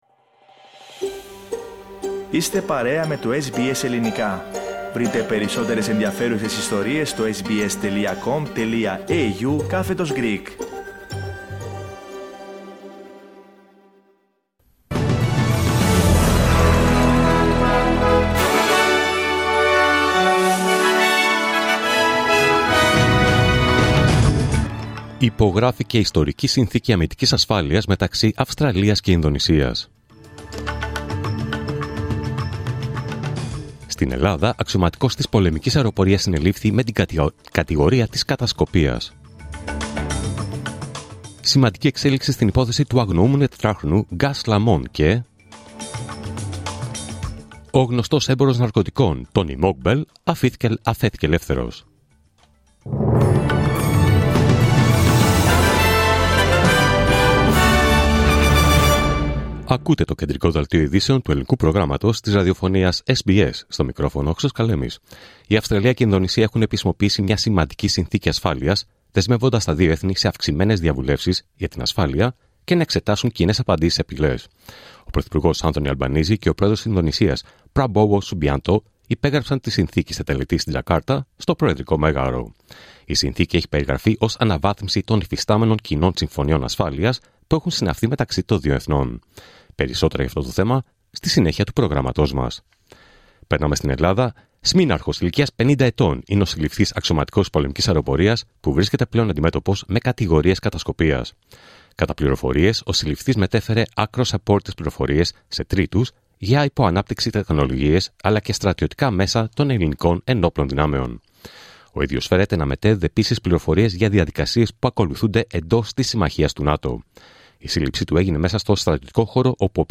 Δελτίο Ειδήσεων Παρασκευή 6 Φεβρουαρίου 2026